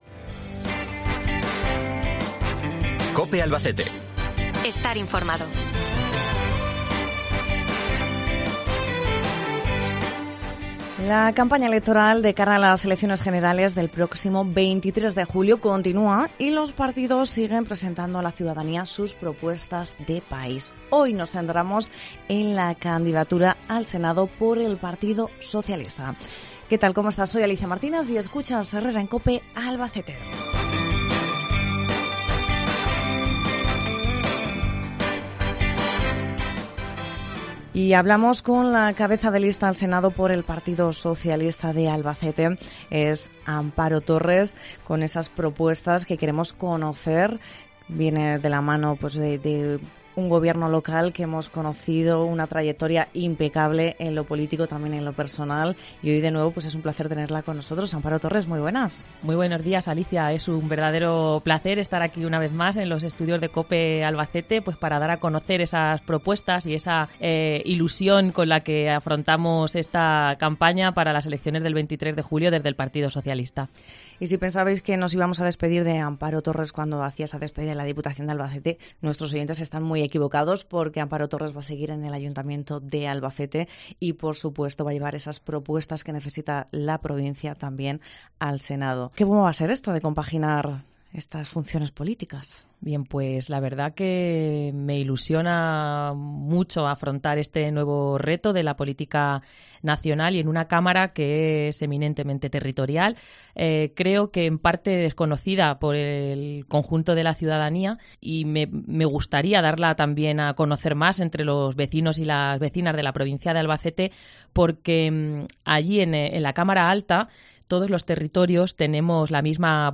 Hoy nos acompaña Amparo Torres , la cabeza de lista al Senado por el PSOE en Albacete , para hablar de las propuestas de la formación liderada por el presidente del Gobierno, Pedro Sánchez, en materia de infraestructuras y otros asuntos importantes para Albacete y la Comunidad Autónoma.